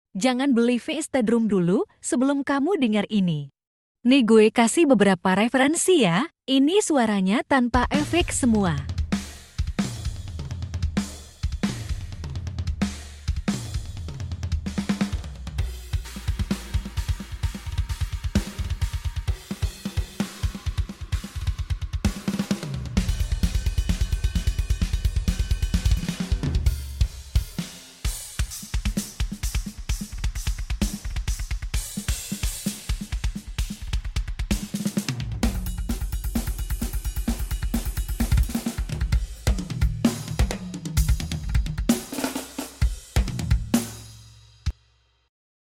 🥁 Vst Drums Sound review sound effects free download
Dengan koleksi suara drum yang kaya dan berkualitas tinggi, Hertz Drum membantu Anda menciptakan track drum yang menghentak.
VST Drum ini menawarkan suara drum yang autentik dan realistis, yang direkam dengan kualitas studio.